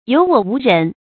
有我無人 注音： ㄧㄡˇ ㄨㄛˇ ㄨˊ ㄖㄣˊ 讀音讀法： 意思解釋： 極言其自傲而輕視他人。